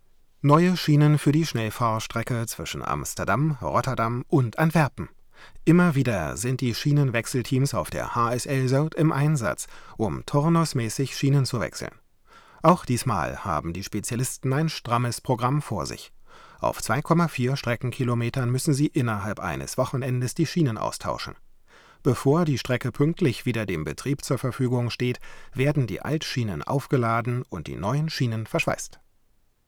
Der große Sprecherraum-Mikrofonvergleich
Das MT 71s ohne EQ, nur mit TM 119: Dein Browser kann diesen Sound nicht abspielen.